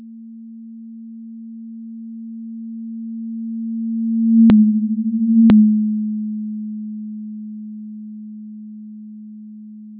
Repeat part 01, but assume that the observer is a human listening binaurally. In effect, generate a stereo output, as if there were 2 observers on the sound source trajectory, at a certain distance apart from each other.